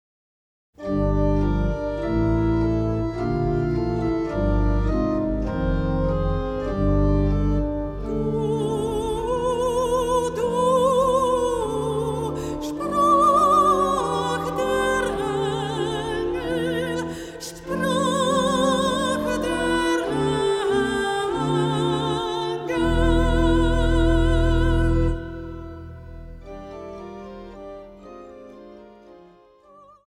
Neue geistliche Musik für Chor, Orgel und Solisten
für Sopran, Horn und Orgel